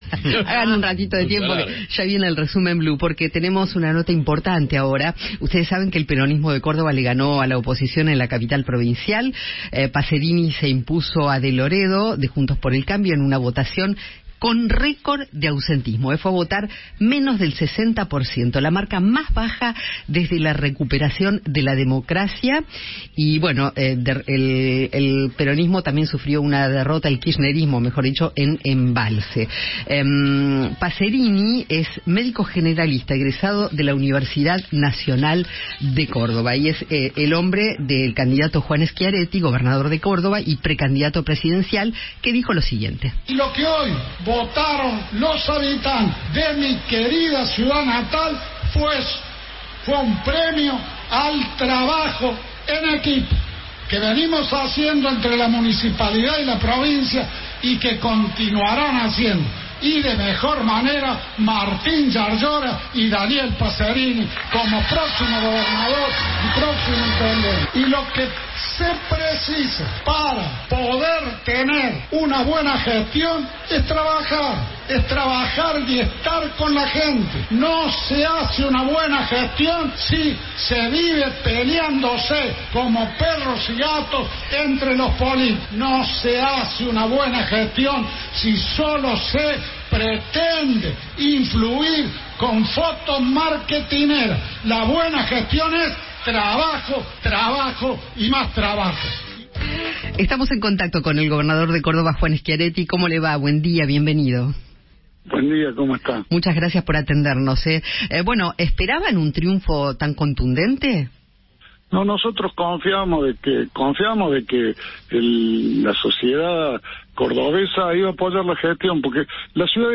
Juan Schiaretti, gobernador de Córdoba y precandidato a Presidente, habló en Alguien Tiene que Decirlo sobre el triunfo de Daniel Passerini como intendente de la capital y apuntó contra el kirchnerismo.